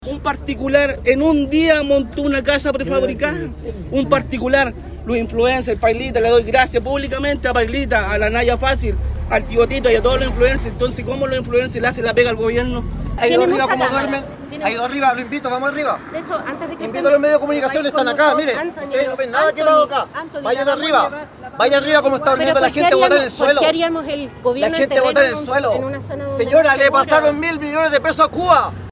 Entre gritos de indignación y recriminaciones fue recibida la ministra de la Mujer, Antonia Orellana, quien visitó Penco para entregar ayudas a mujeres afectadas por los incendios.